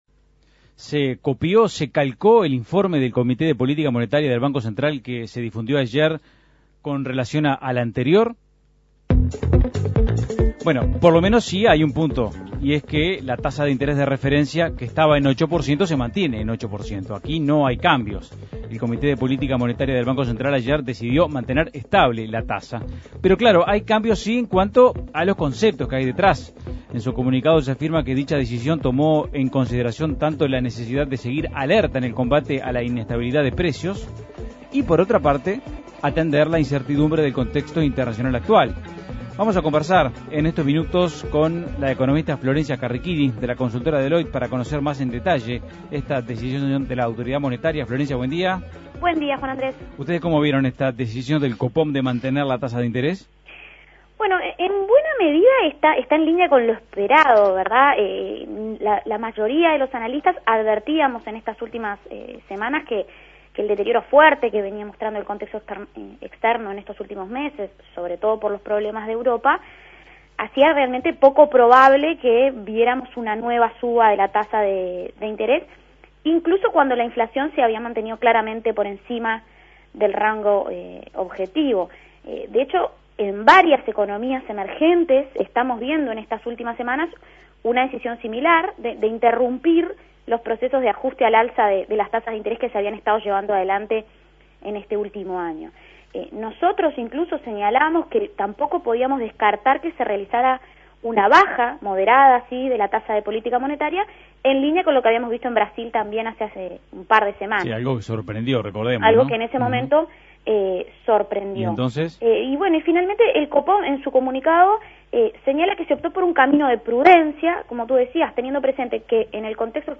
Análisis Económico El Copom mantuvo estable en 8% la tasa de política monetaria en la reunión de ayer.